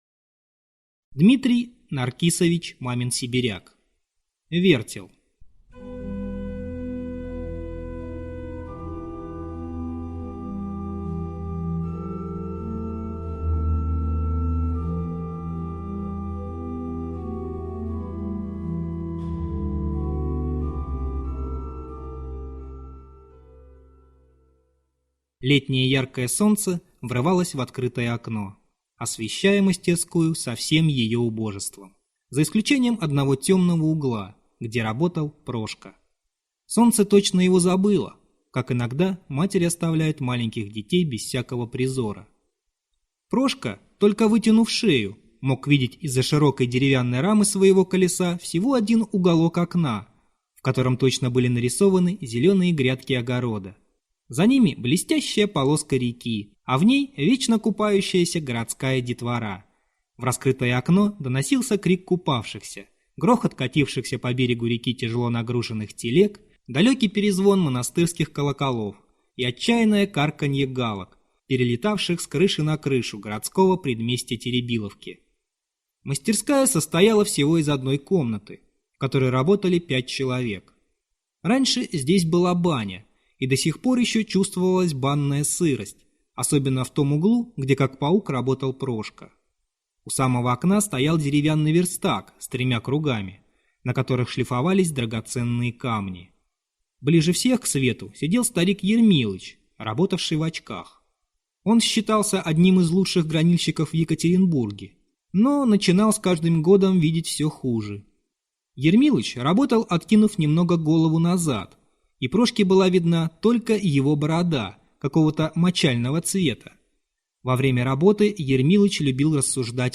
Вертел - аудио рассказ Мамина-Сибиряка Д.Н. Главный герой - подросток Прошка, сирота, работающий в мастерской, чтобы как-то прокормиться.